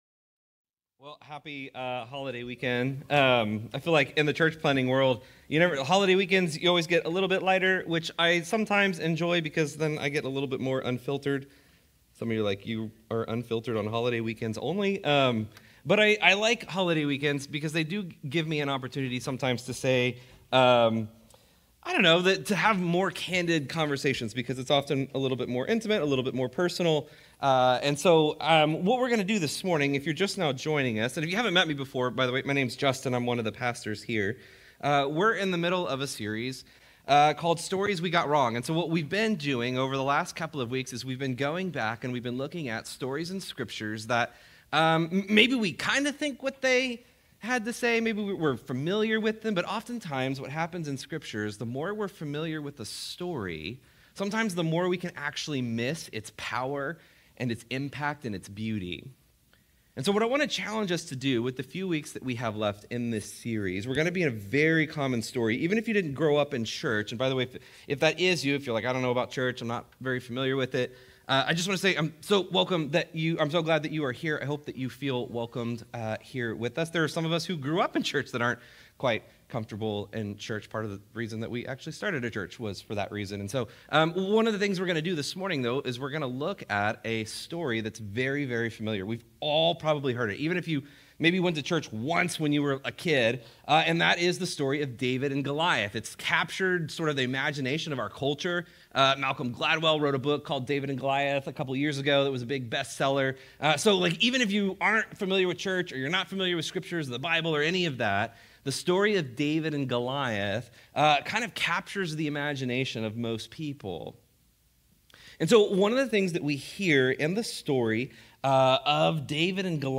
Restore Houston Church Sermons Meant to Break You Jun 23 2025 | 00:39:59 1x 00:00 / 00:39:59 Subscribe Share Apple Podcasts Overcast RSS Feed Share Link Embed